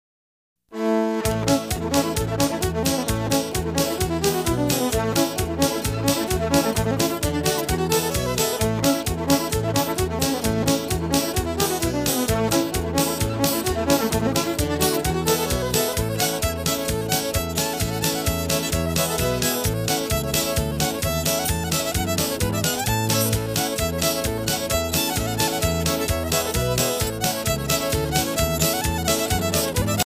Fiddle Music